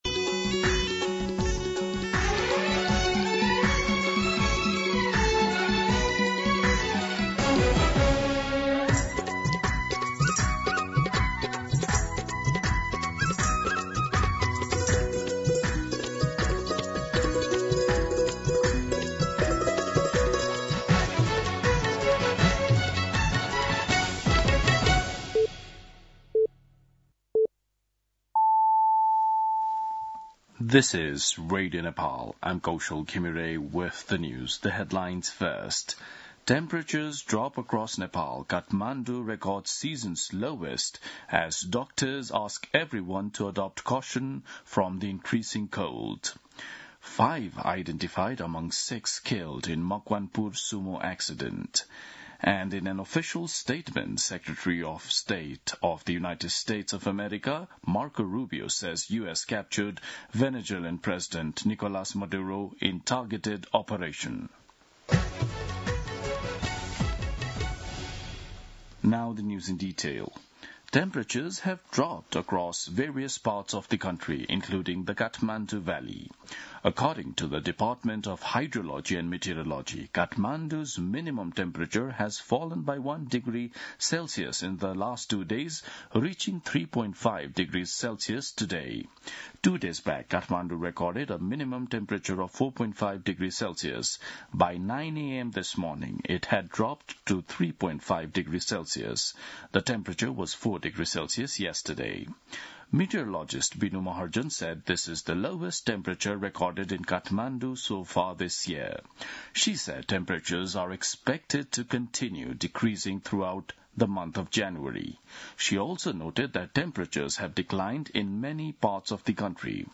दिउँसो २ बजेको अङ्ग्रेजी समाचार : २१ पुष , २०८२
2pm-English-News-21.mp3